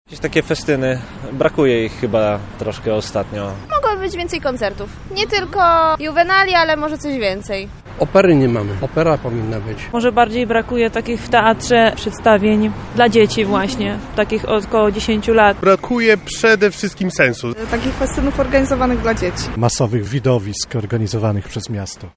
Zapytaliśmy mieszkańców Lublina czego ich zdaniem wciąż brakuje w lubelskiej kulturze i co powinno się zmienić.
sonda